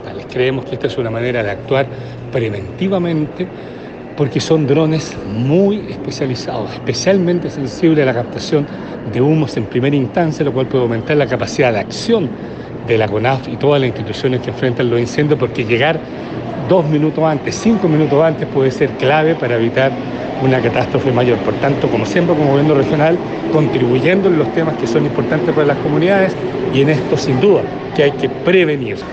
Este proyecto ha sido trabajado técnicamente con CONAF, y según detalló el Gobernador Regional, contribuirá contribuir a la prevención, detención y al combate de los incendios forestales.
16-octubre-24-patricio-vallespin-prevencion.mp3